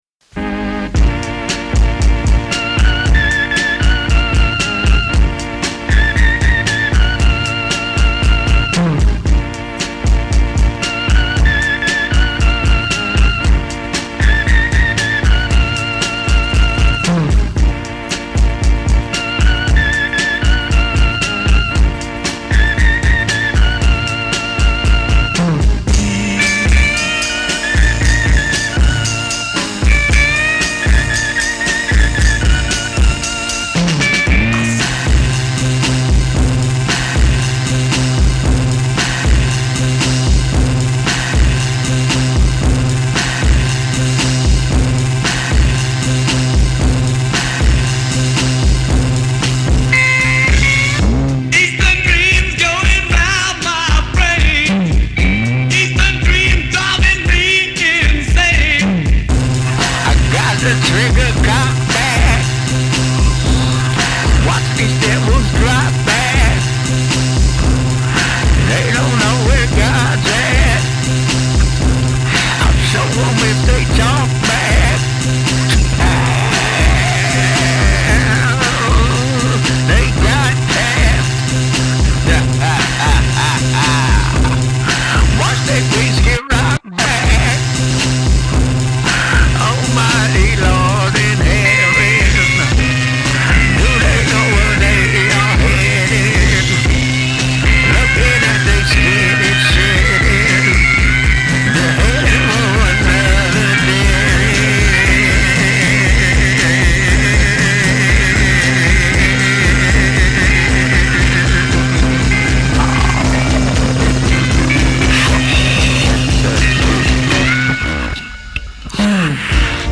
The album doesn’t even sound like reality.
Everything is distorted, pushed far into the red.